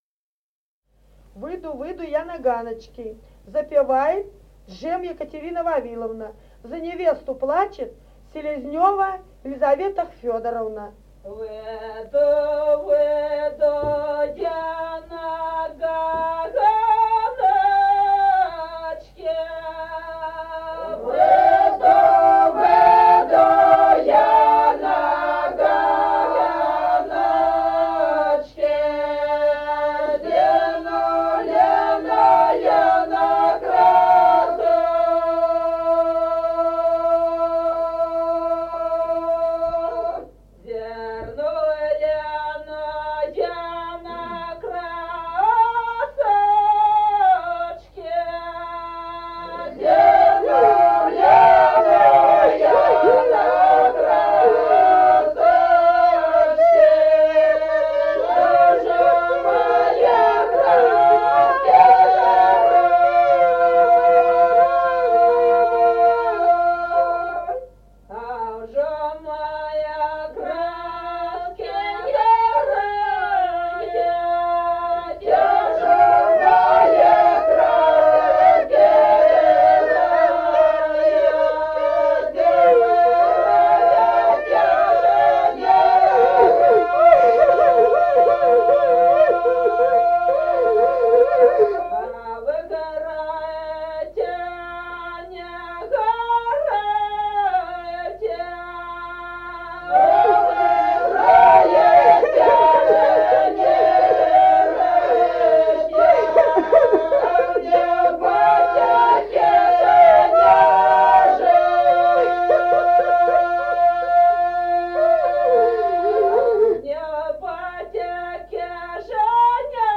Песни села Остроглядово. Выйду, выйду я на ганочки (с плачем).